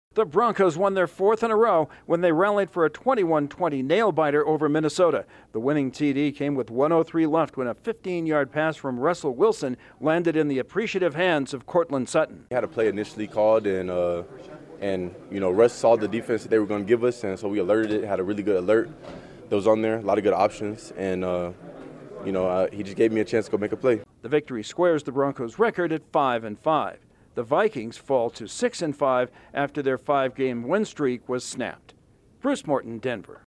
reports from Denver.